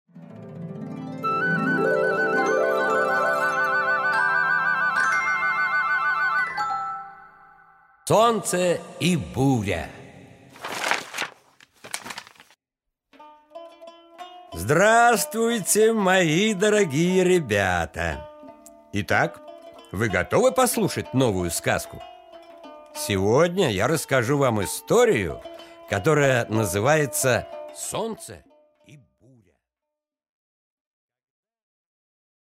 Аудиокнига Солнце и буря | Библиотека аудиокниг